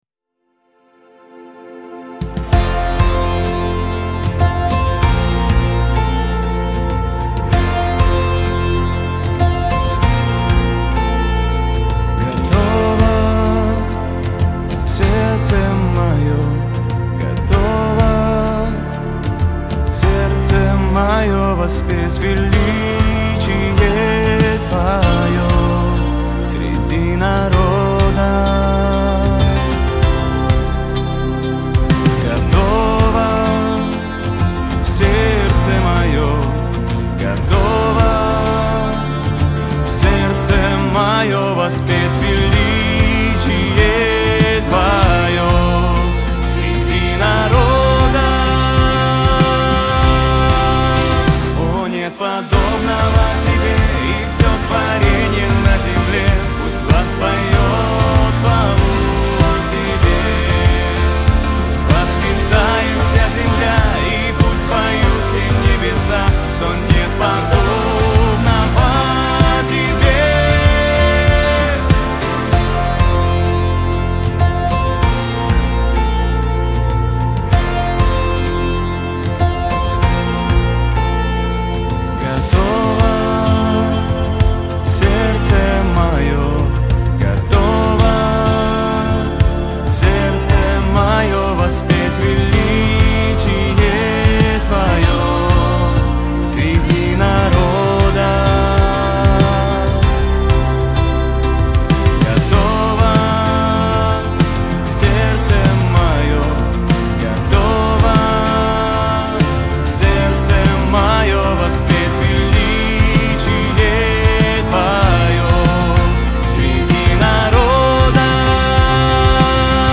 240 просмотров 278 прослушиваний 4 скачивания BPM: 96
2025 single